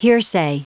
Prononciation du mot hearsay en anglais (fichier audio)
Prononciation du mot : hearsay